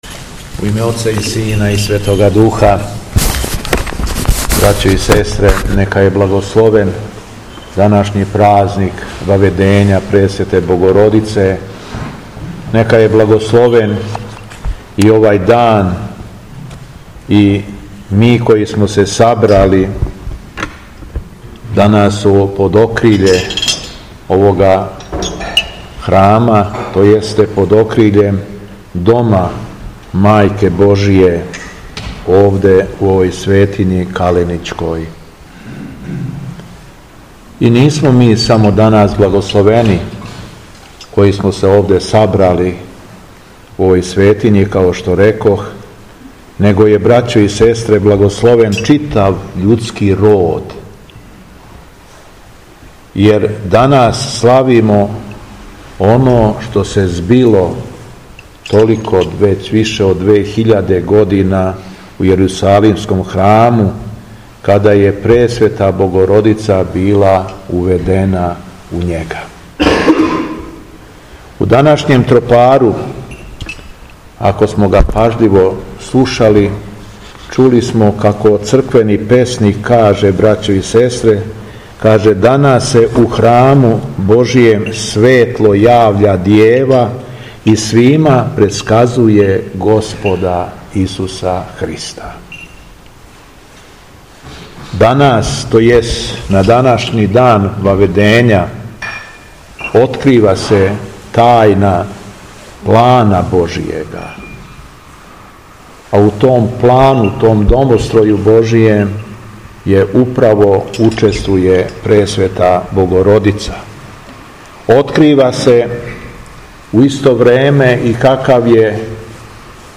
Беседа Његовог Високопреосвештенства Митрополита шумадијског г. Јована
Владика Јован је беседио врло надахнуто: